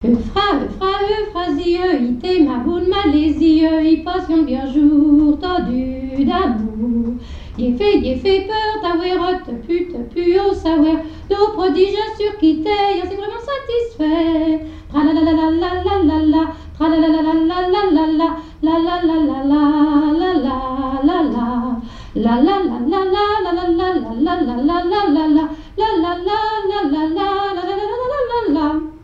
danse : polka
airs de danses et chansons traditionnelles
Pièce musicale inédite